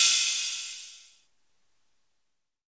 TM-88 Crash #01.wav